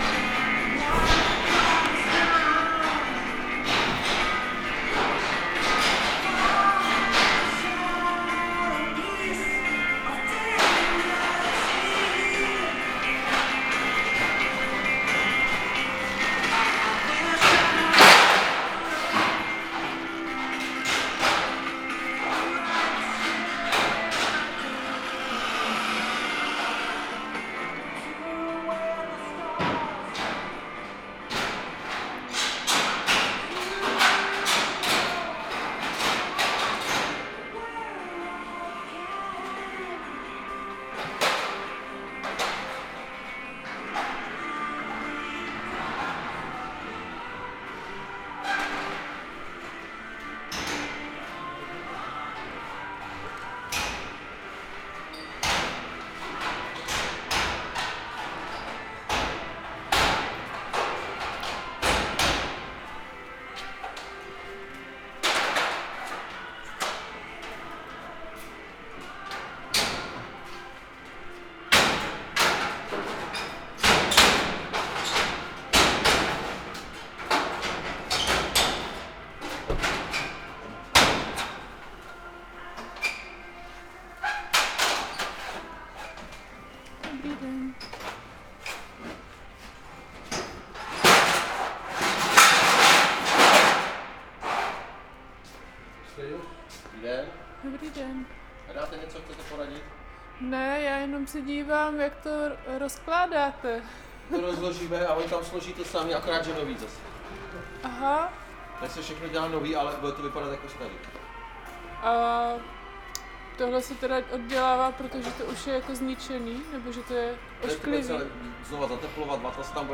Nahoře je hluk, rádio, zvuky oprav, nářadí, hlasy.
Meandry zvuku (v) prostoru
Kudy se zvuk nese, jak se od hmoty odráží, do čeho se vsakuje, a je vpuštěn, proniká.
rozhovor.wav